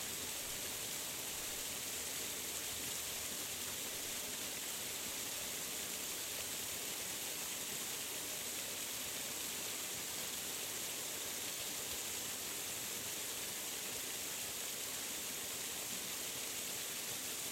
Waterfall lower noise
Duration - 17 s Environment - Mountains, light breeze, rivers, waterfall, people walking about. Description - Waterfall, pouring, crashing, lower noise, further distance.